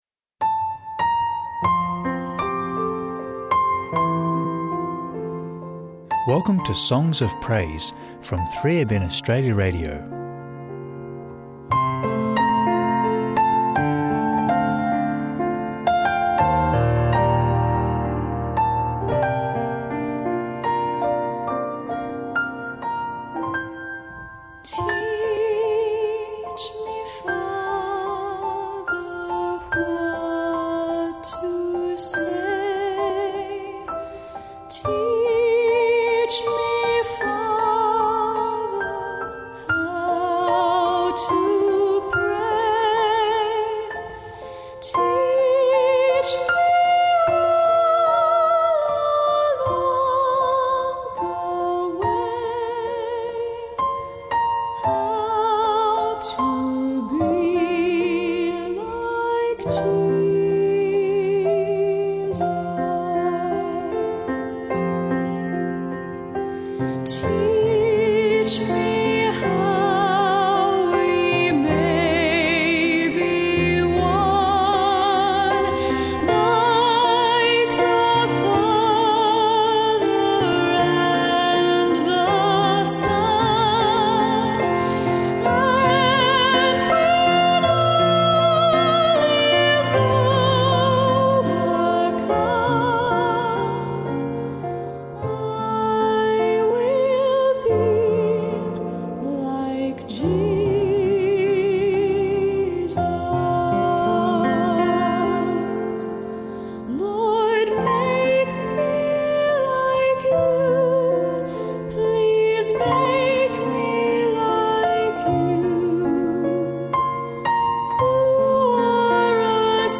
uplifting Christian hymns and worship music